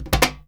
percussion 05.wav